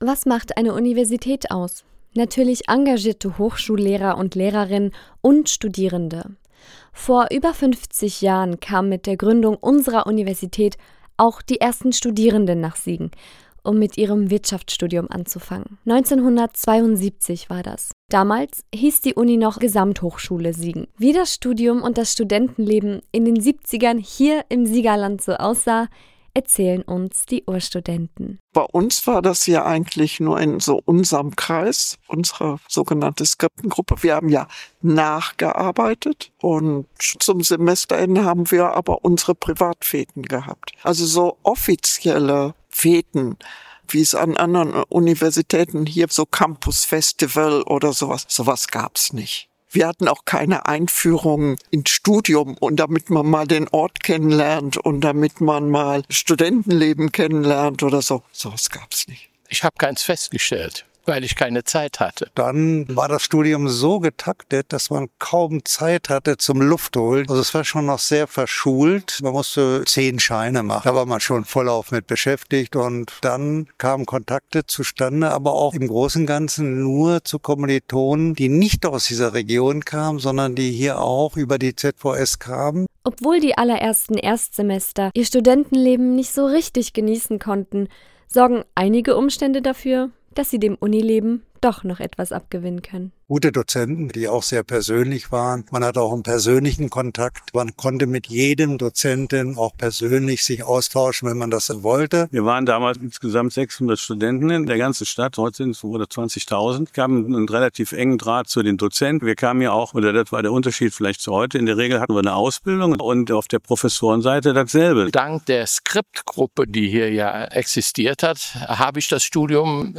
Audioreportage